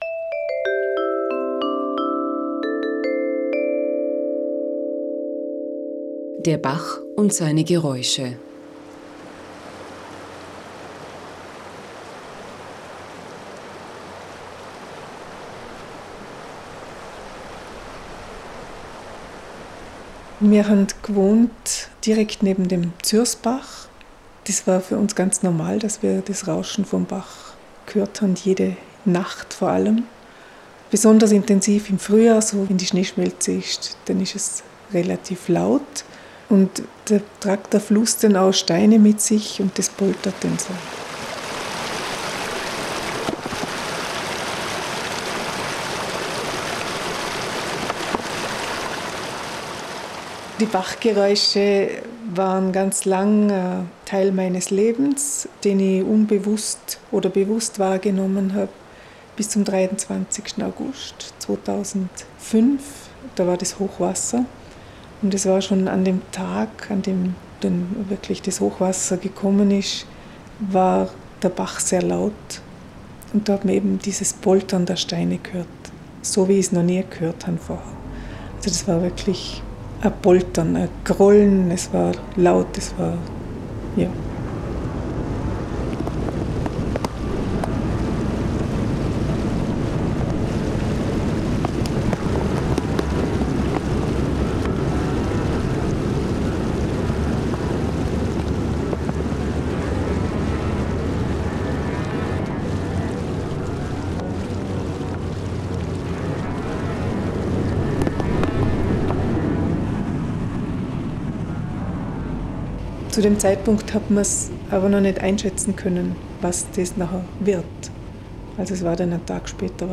Menschen aus Lech erzählen, welche Geräusche sie in ihrem Alltag begleiten und welche Töne in ihrer Biografie eine Rolle gespielt haben.
Ihre Erzählungen und die damit assoziierten Geräusche verbinden sich mit den eigens dazu komponierten und eingespielten Musikfragmenten zu neun sehr unterschiedlichen Klangreisen in vergangene und gegenwärtige Welten.